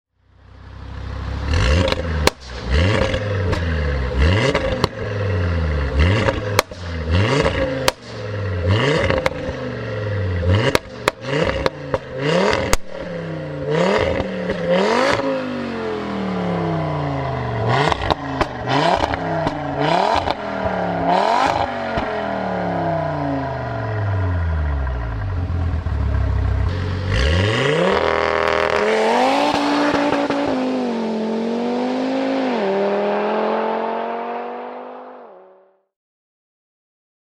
Audi RS3 mit Remus-Auspuff